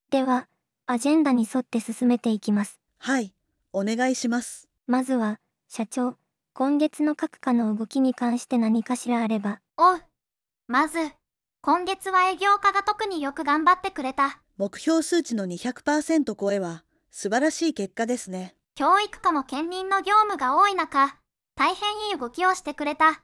それでは、実際の会議でのやりとりを音声合成ソフトに置き換えてみましょう。
・会議風景（置き換えた場合）
こちらの方がやはり華があります。
※この記事で使用した人工音声はVOICEVOXを利用しています